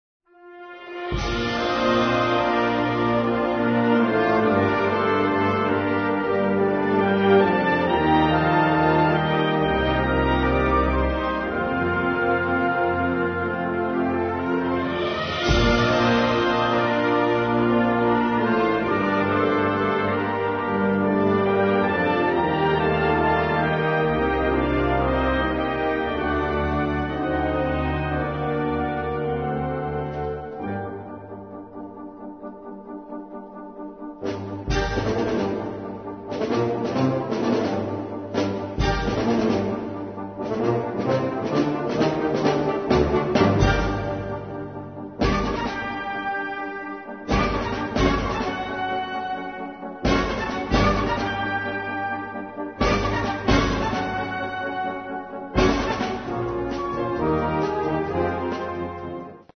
Categoria Concert/wind/brass band
Sottocategoria Musica per fiati contemporanea (1945-oggi)